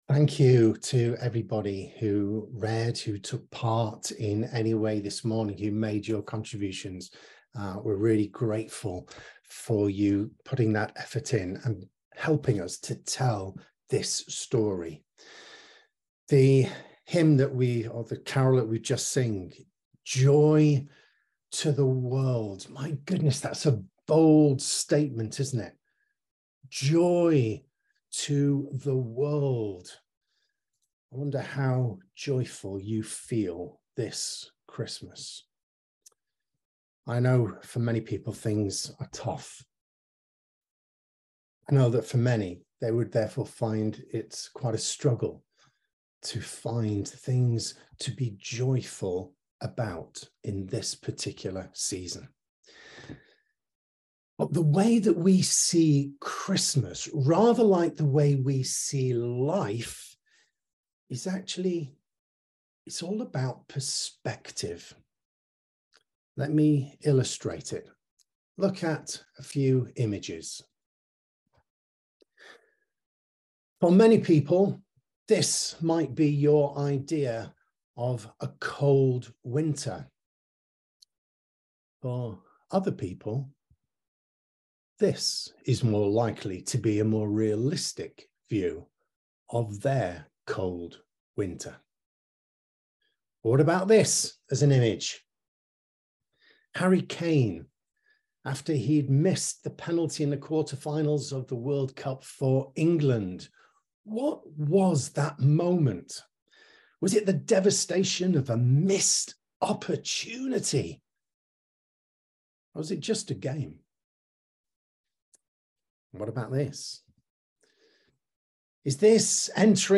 Carol service reflection